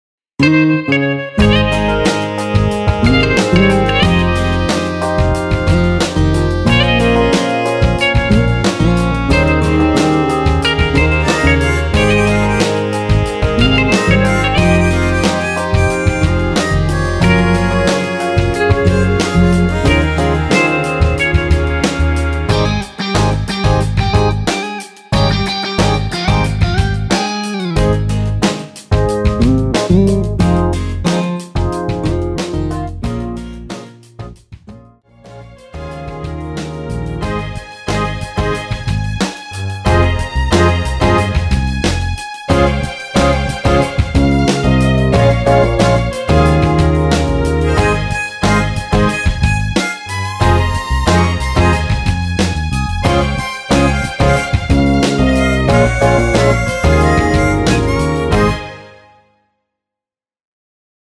엔딩이 페이드 아웃이라 라이브 하기시 좋게 원곡에서 4분 15초 쯤에 엔딩을 만들었습니다.(미리듣기 참조)
Ab
앞부분30초, 뒷부분30초씩 편집해서 올려 드리고 있습니다.
중간에 음이 끈어지고 다시 나오는 이유는